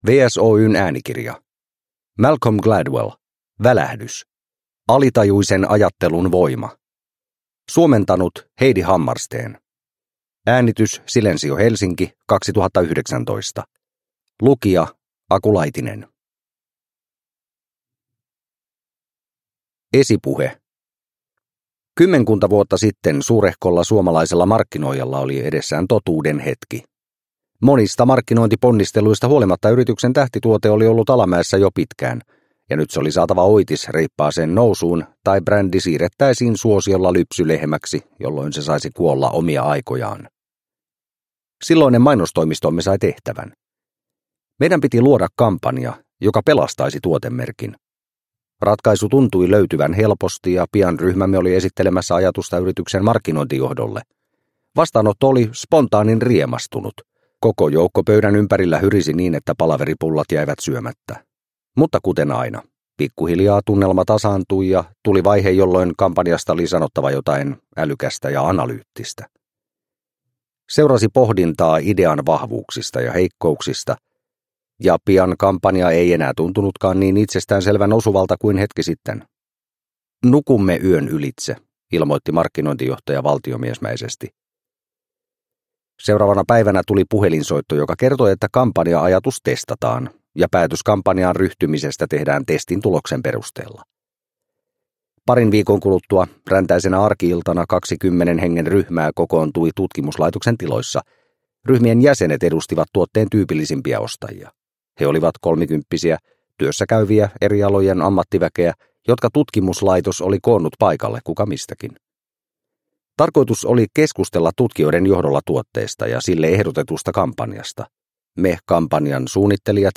Välähdys – Ljudbok – Laddas ner